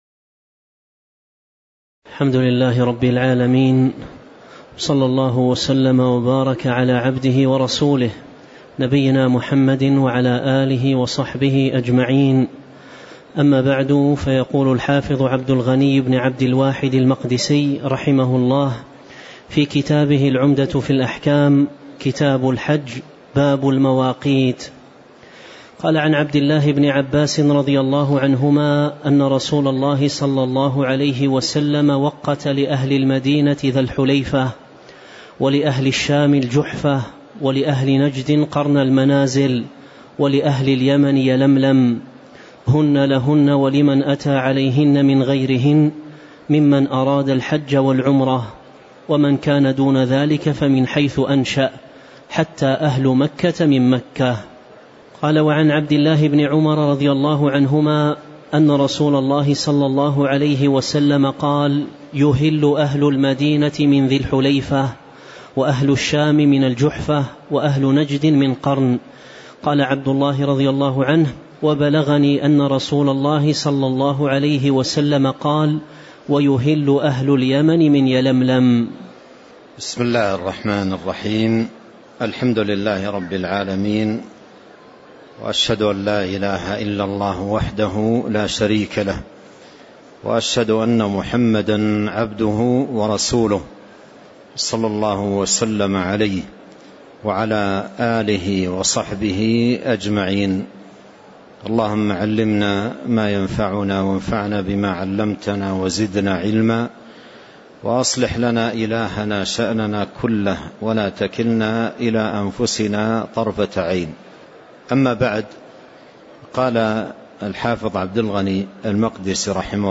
تاريخ النشر ٣٠ ذو القعدة ١٤٤٣ هـ المكان: المسجد النبوي الشيخ